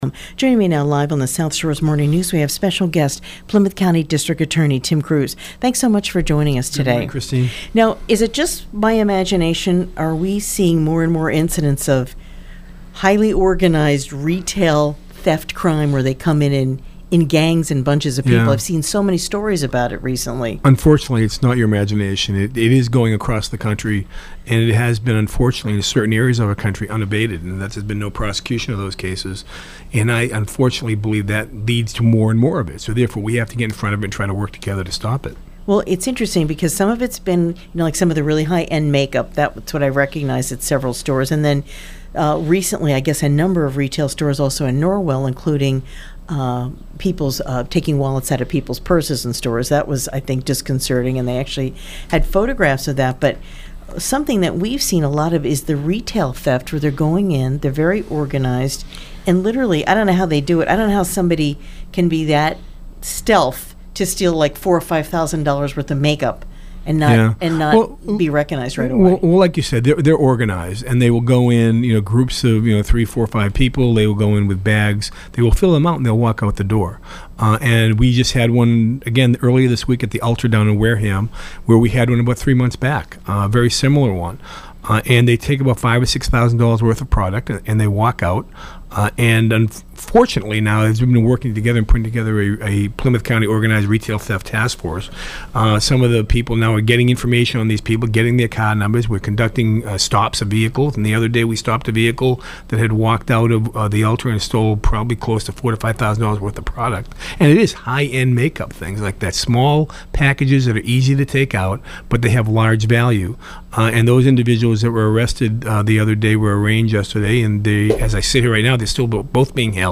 Plymouth County District Attorney Tim Cruz speaks